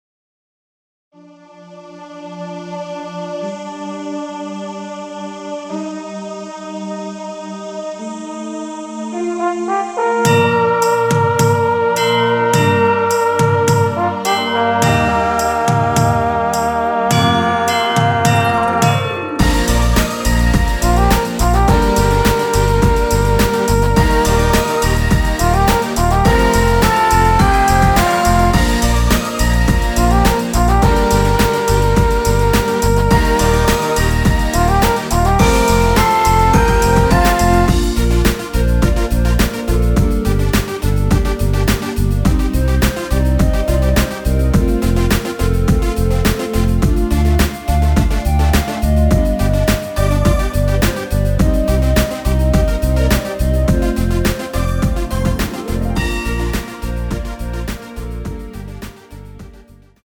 원키 멜로디 포함된 MR입니다.(미리듣기 확인)
Ab
앞부분30초, 뒷부분30초씩 편집해서 올려 드리고 있습니다.